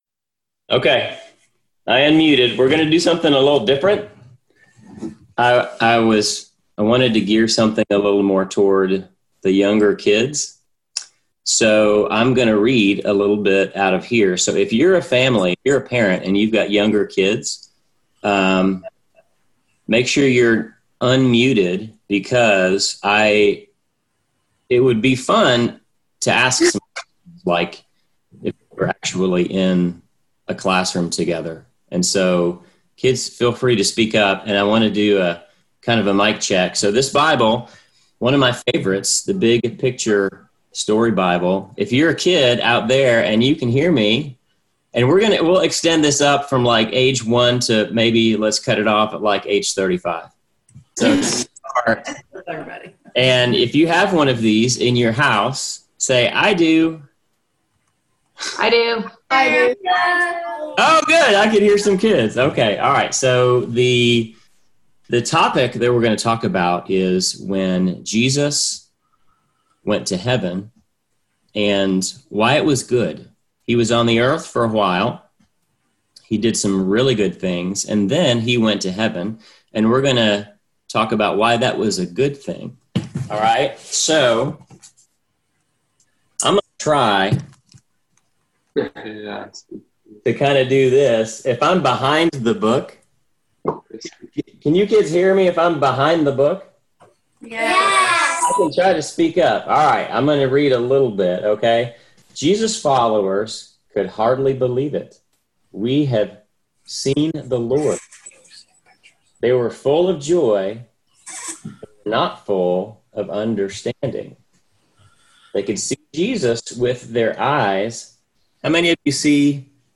Sermonette A 5/22: A Word for the Kids: The Ascension Story